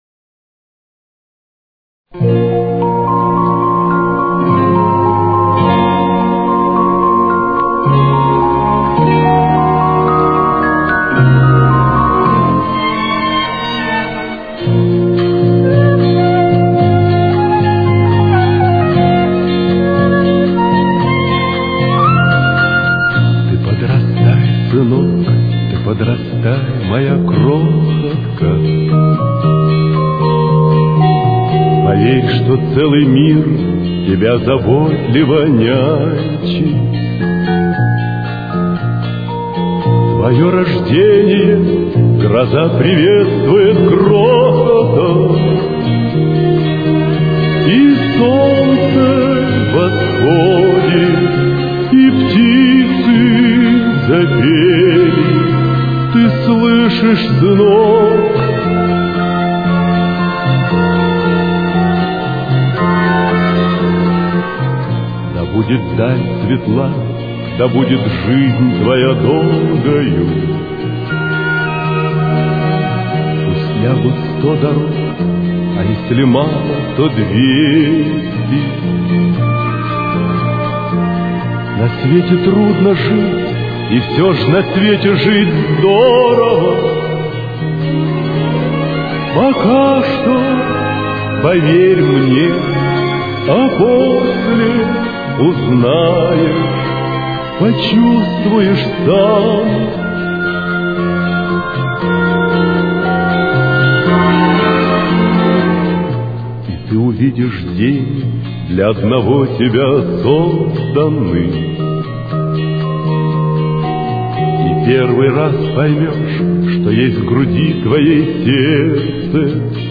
с очень низким качеством (16 – 32 кБит/с)
Си-бемоль минор. Темп: 118.